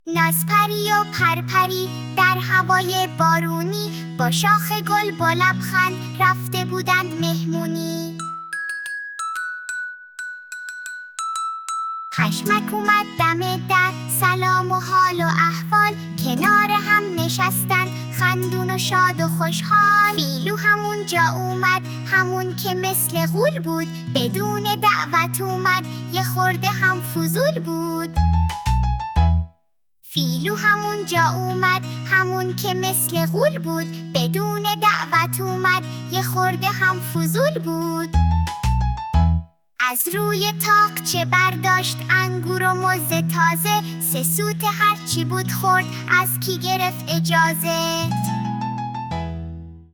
فعالیت 3: شعر